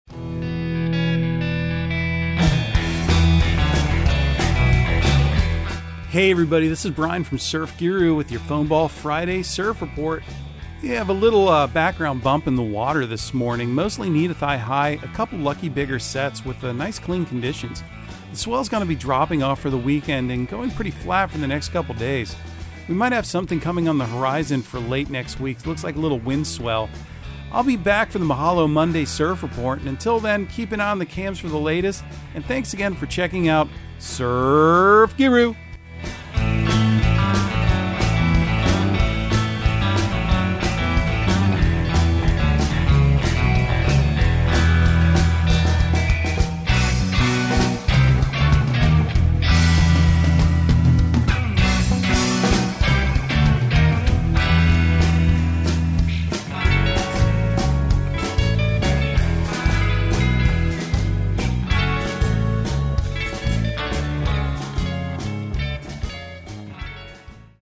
Surf Guru Surf Report and Forecast 03/25/2022 Audio surf report and surf forecast on March 25 for Central Florida and the Southeast.